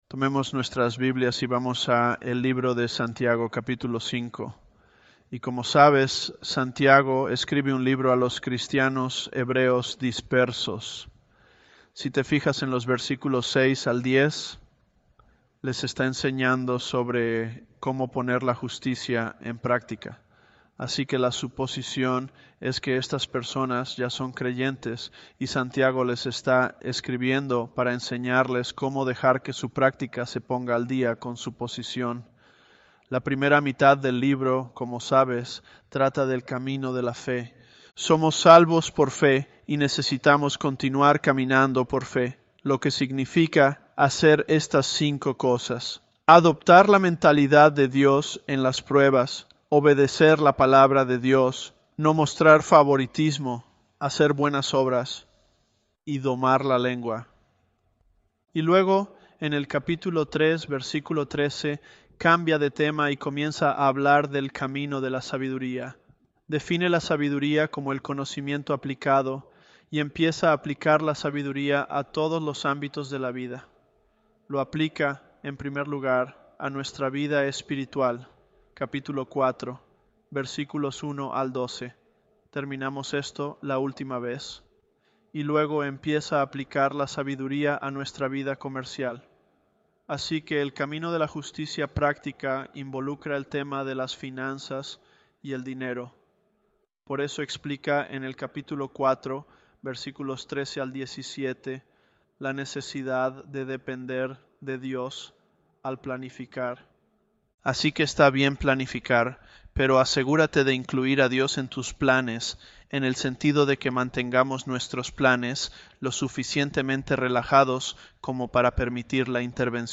ElevenLabs_James026b.mp3